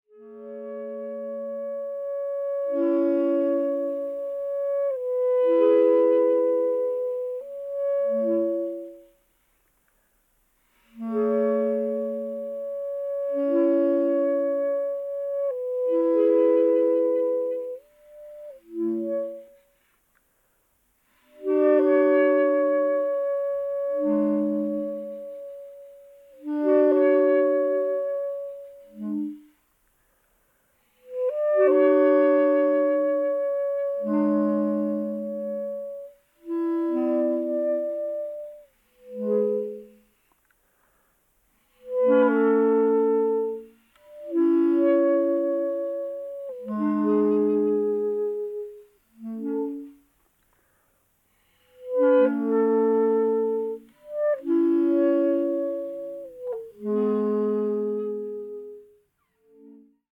クラリネットだけでため息のような音楽を奏でる